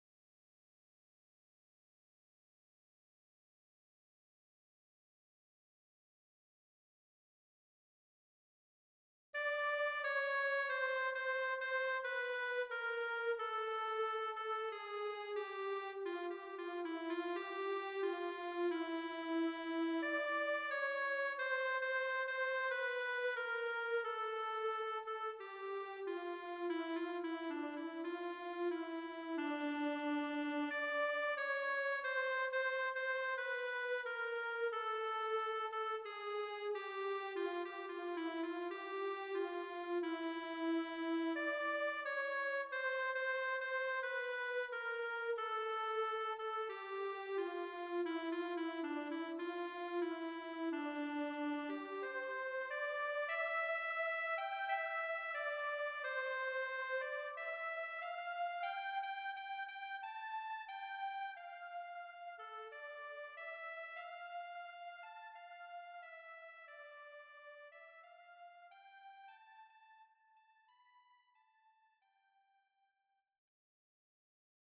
：B-MIDIでメロディをひろい、歌いながら発音の練習をします。
テンポ スロー2
midi_tempo_45.mp3